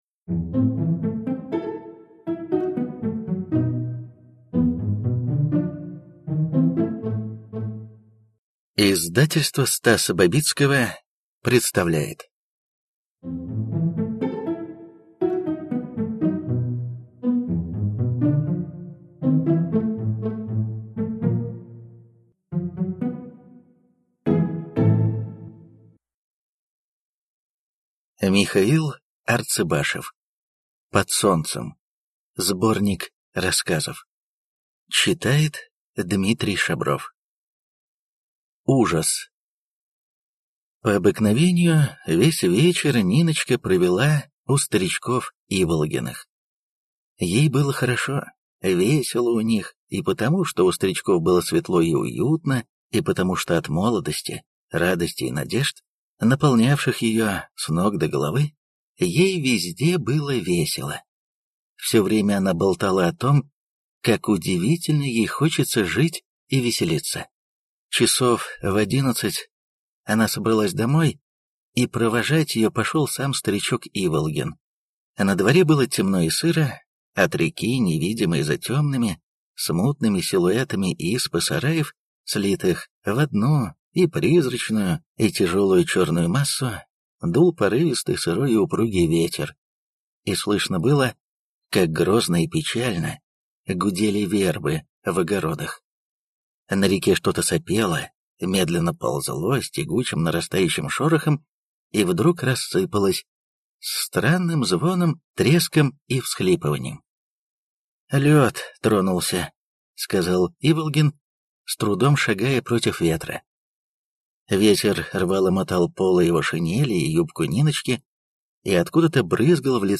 Аудиокнига Под солнцем | Библиотека аудиокниг